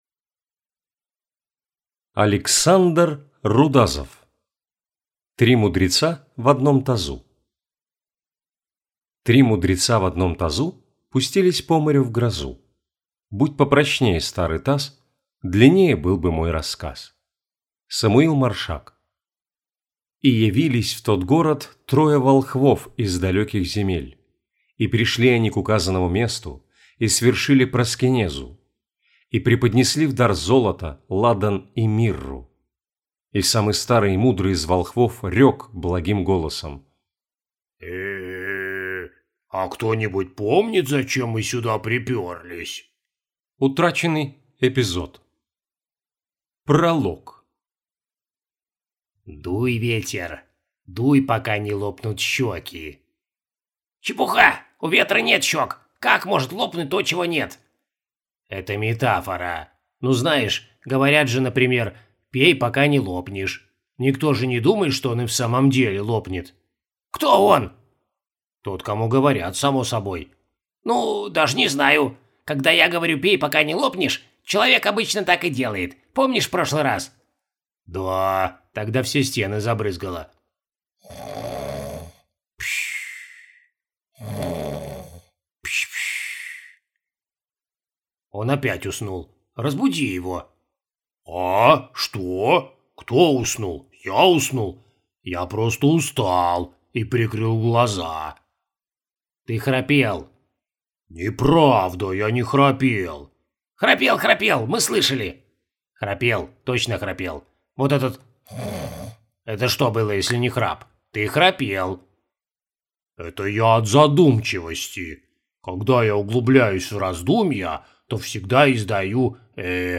Аудиокнига Три мудреца в одном тазу | Библиотека аудиокниг